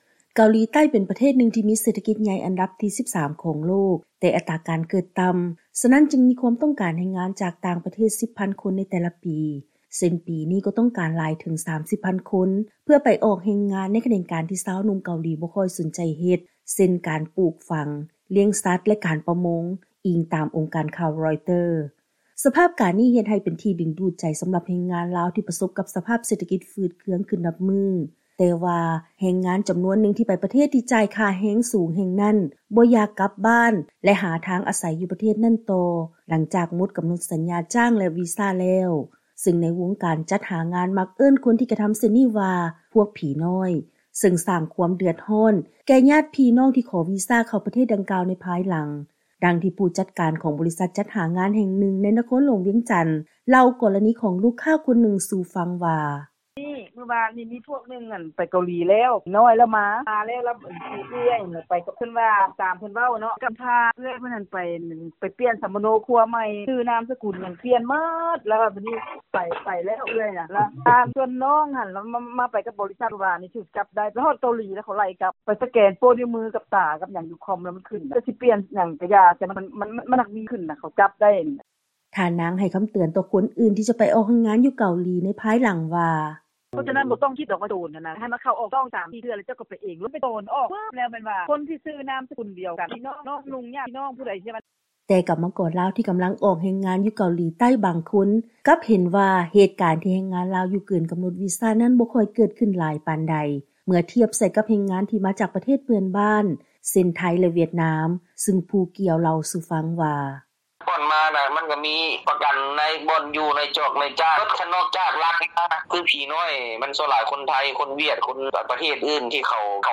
ເຊີນຟັງລາຍງານ ແຮງງານລາວທີ່ໄປເຮັດວຽກຢູ່ເກົາຫຼີໃຕ້ ແລ້ວບໍ່ຕ້ອງການຢາກກັບບ້ານຕາມກໍານົດເວລາ ໄດ້ສ້າງຄວາມເດືອດຮ້ອນໃຫ້ແກ່ຍາດພີ່ນ້ອງໃນພາຍຫຼັງ